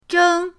zheng1.mp3